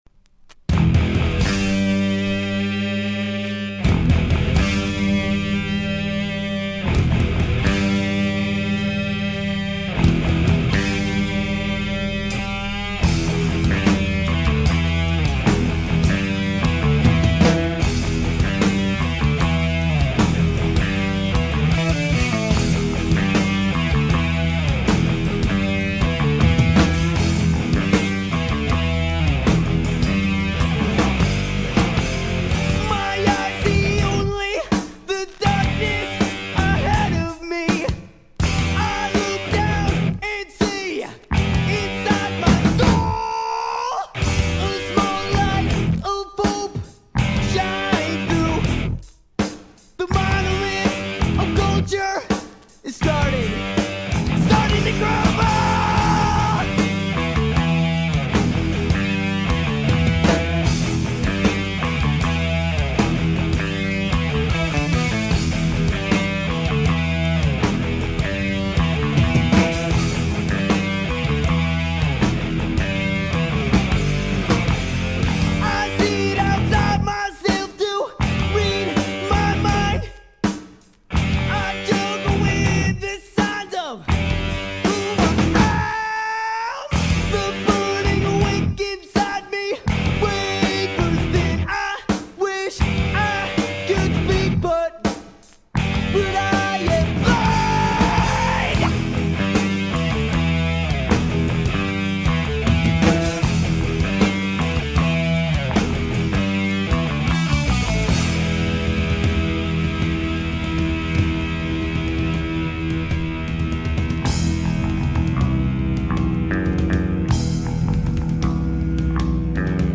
Vocals/Bass
Guitar
Drums
Emo